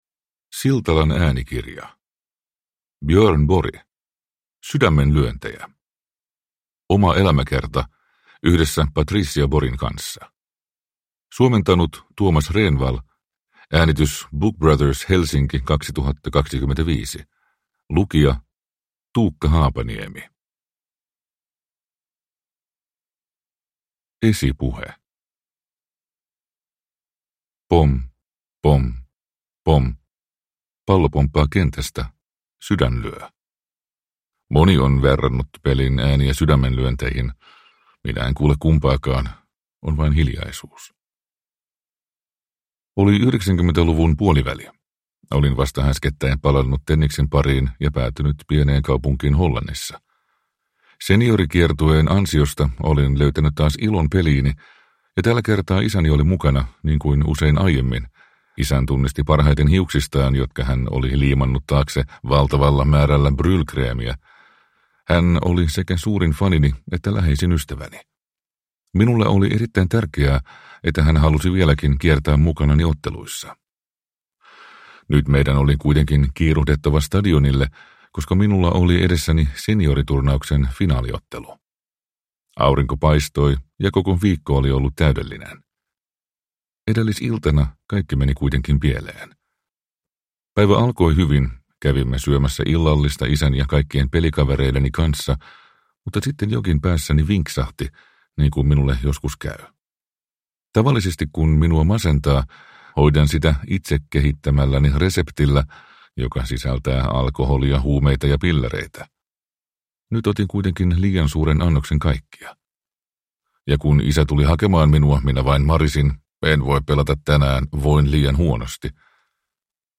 Sydämenlyöntejä (ljudbok) av Björn Borg